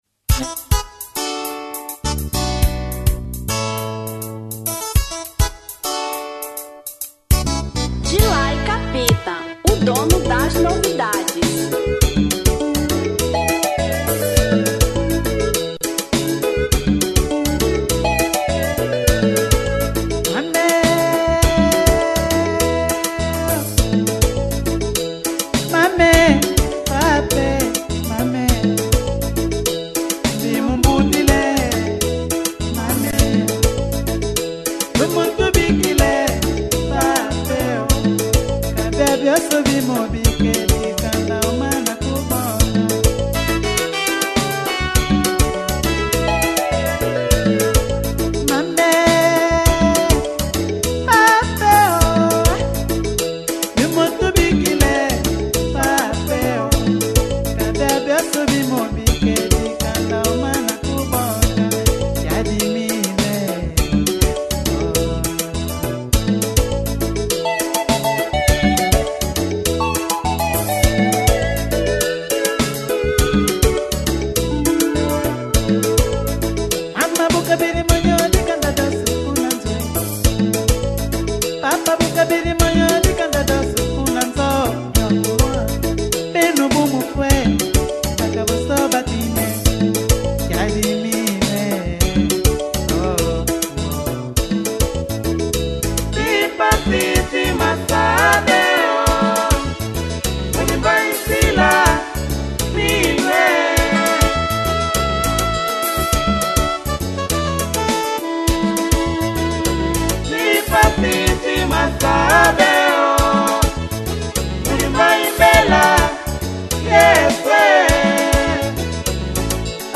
Rumba 2005